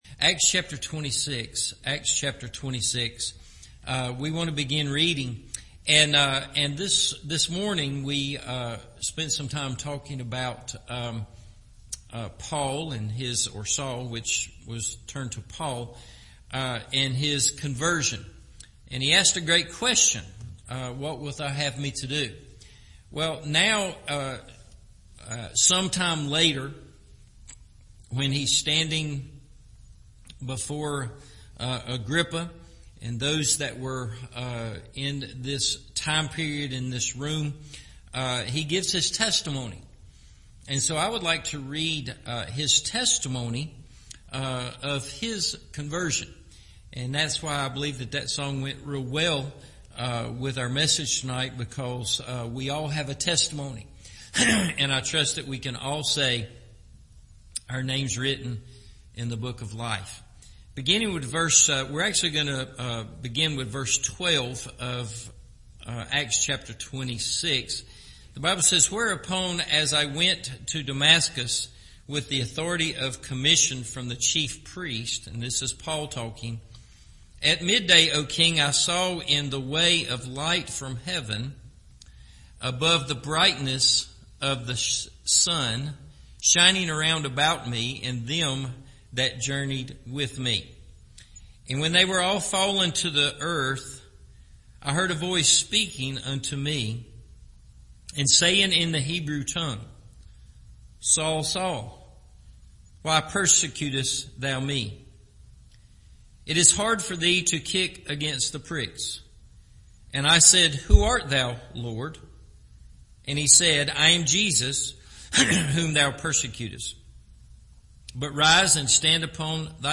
What Others Need To Know – Evening Service